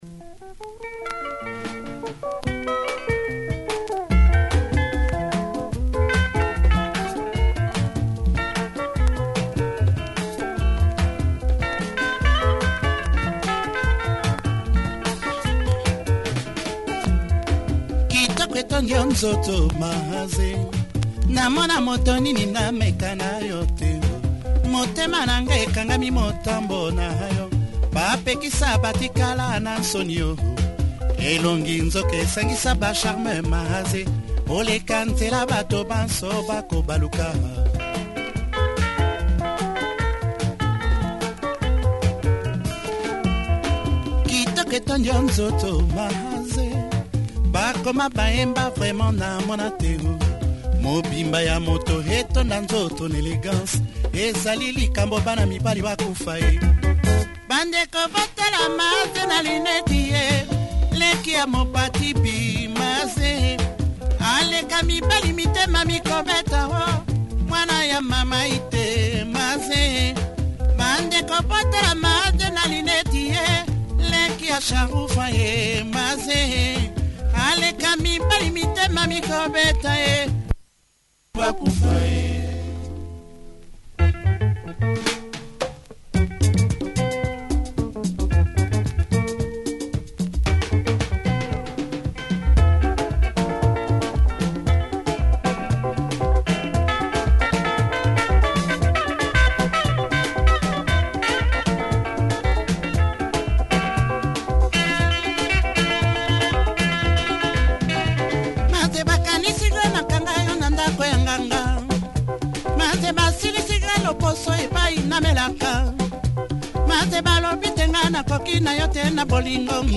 Another top dancer and party number